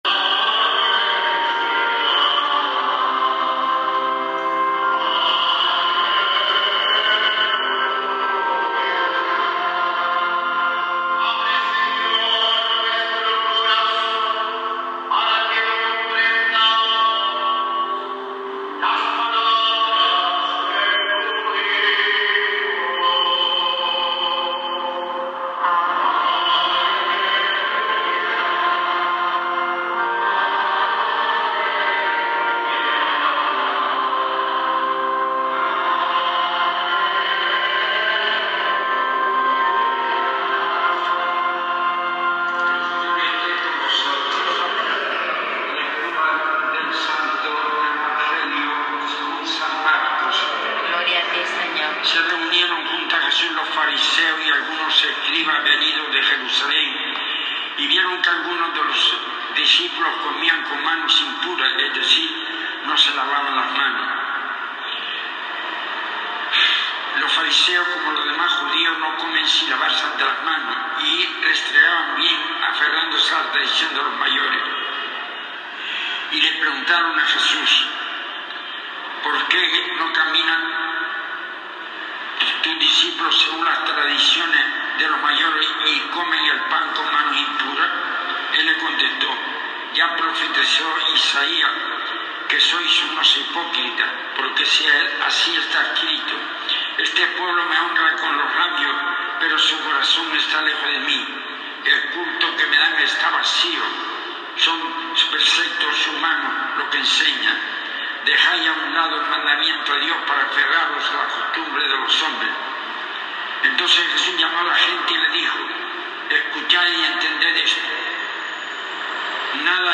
Homilias